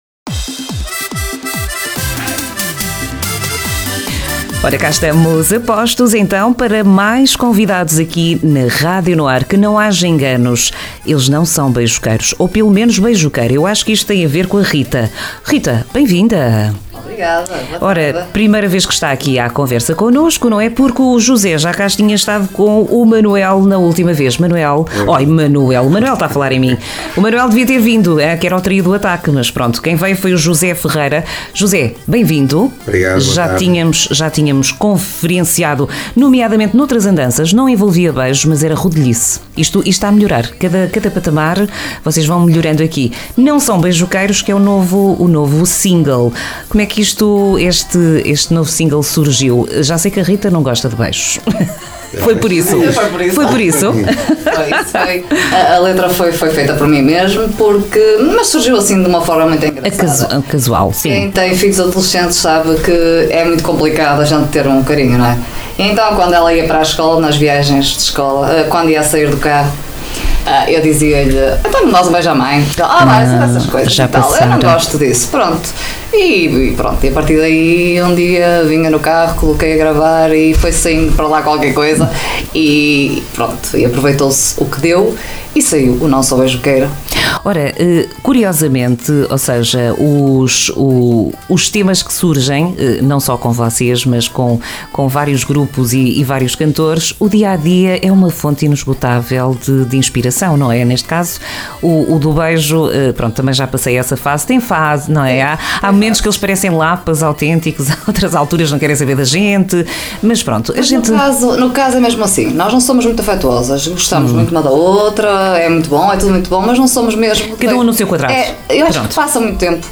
Entrevista Os Turistas, dia 26 de Maio 2025.